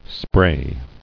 [spray]